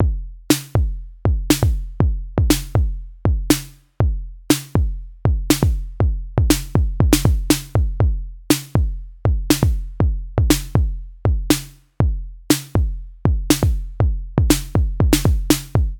The Kick drum with a small amount of low end EQ
The Snare is treated to a bit of Kong’s excellent Rattler and a touch of reverb
The kick and snare play back in a basic pattern